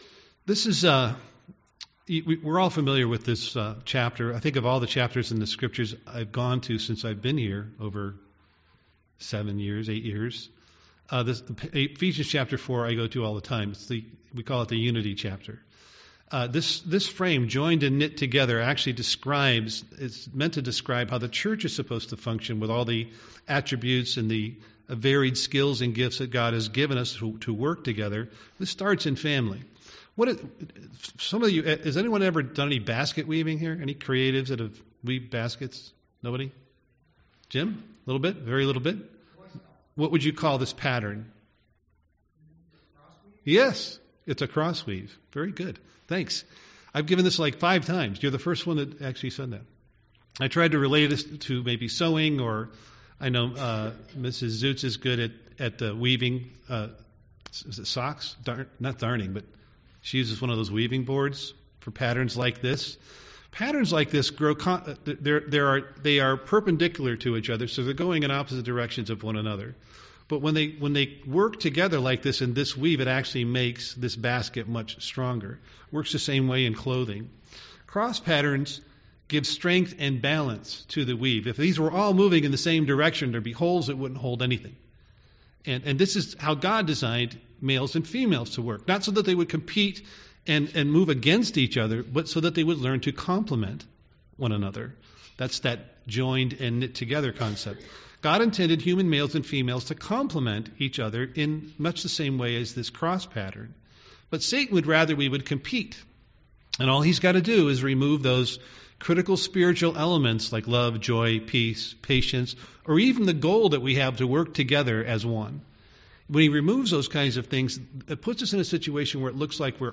UCG Sermon men women gender roles Studying the bible?